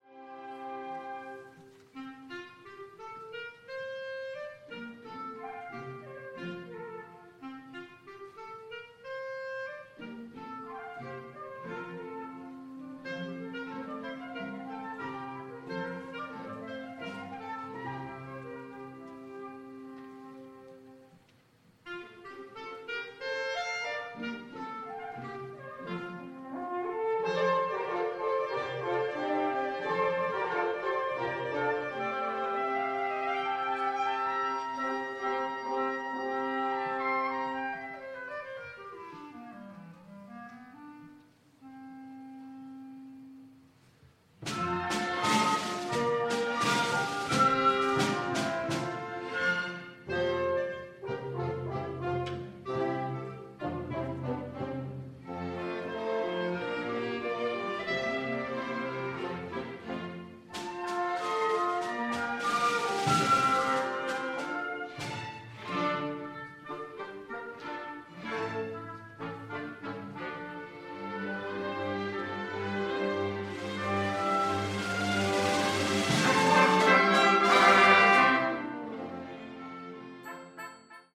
+ - Weihnachtskonzert 2025* Click to collapse